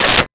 Telephone Receiver Put Down 01
Telephone Receiver Put Down 01.wav